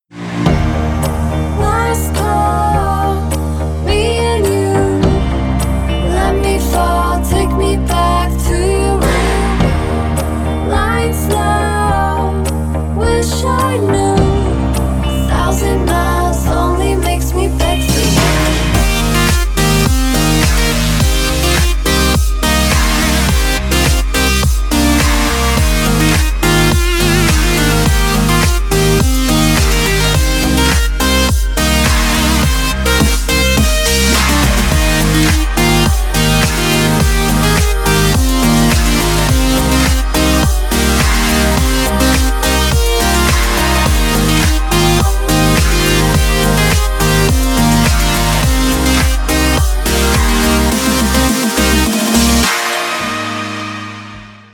• Качество: 320, Stereo
dance
club